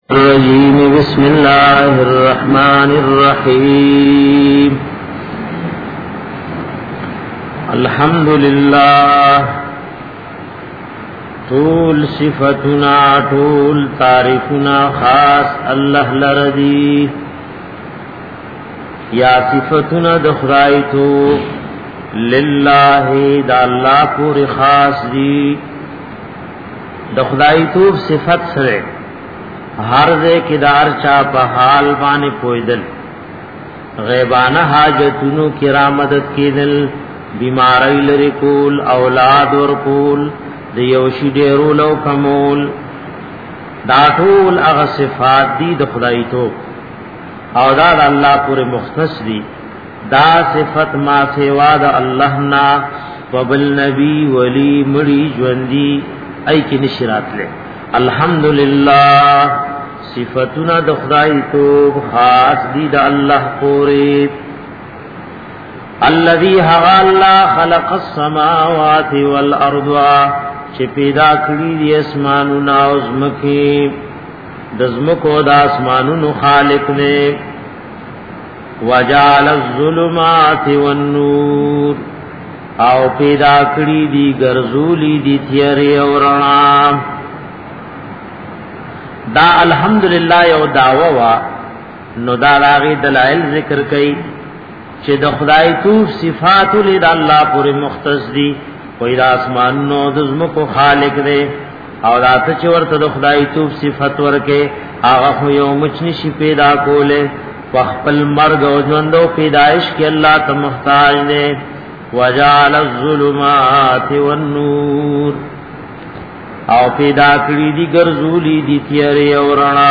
Darse-e-Quran